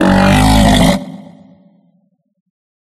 Monster6.ogg